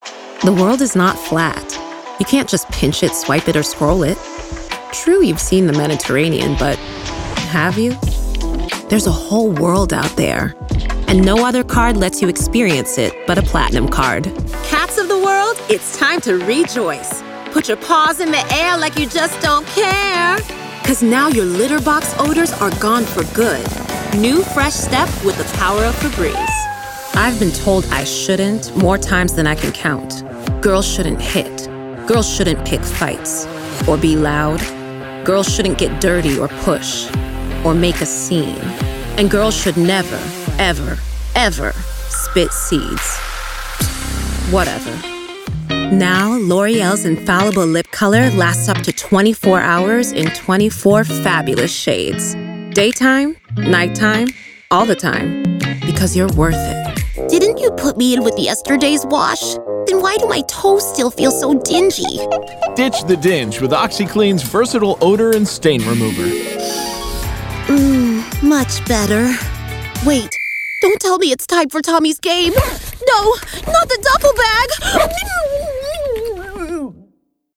Voiceover
Character Demo